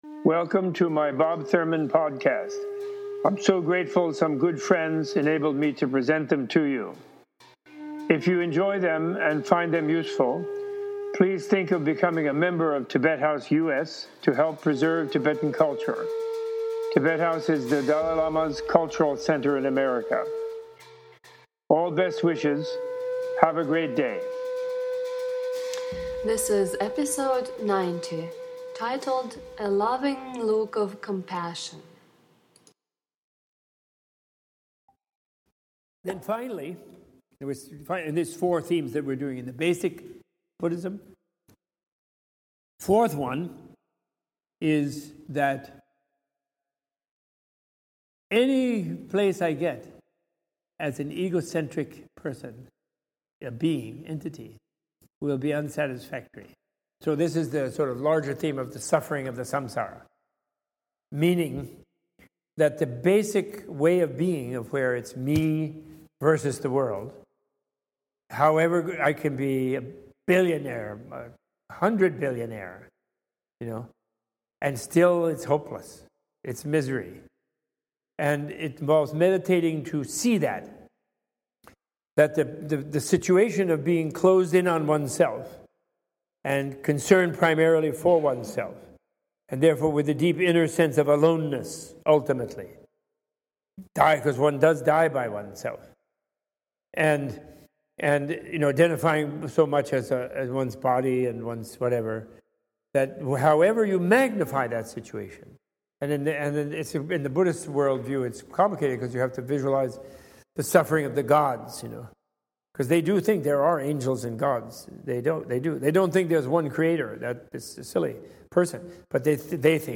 In this podcast Professor Thurman teaches that realizing the infinity of our past lives enables us to find a loving face of our mother in every being, Every being has this loving look of total nurturing, love and concern. This episode is an excerpt from the lecture given at Tibet House US in New York City, September 4, 2013, as part of a Tibetan Buddhism Series.